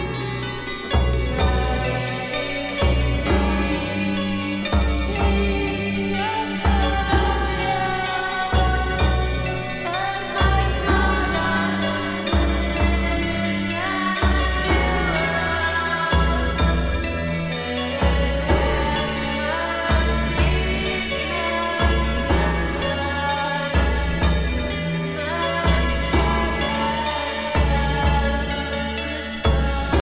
de tradição gótica apurada
as caixas de ritmos não perdoam na cadência marcial